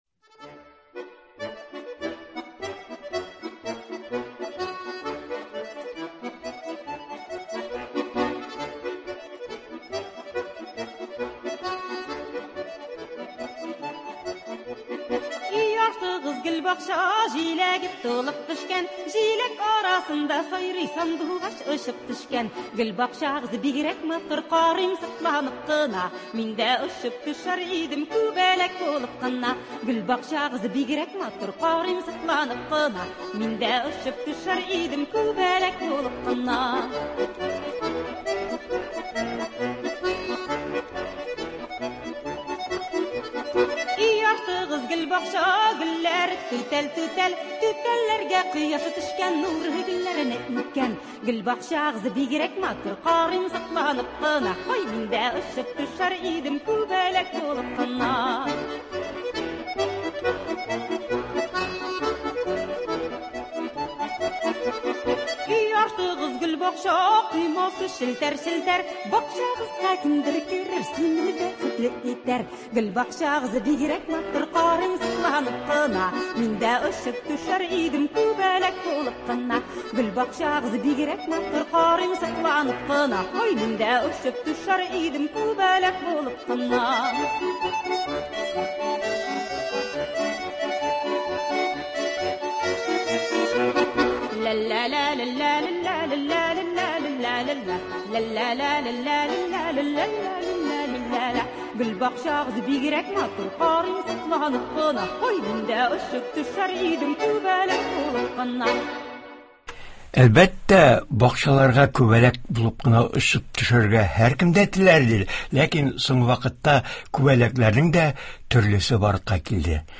Туры эфир (07.06.21)